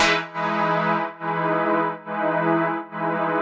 Index of /musicradar/sidechained-samples/140bpm
GnS_Pad-MiscA1:2_140-E.wav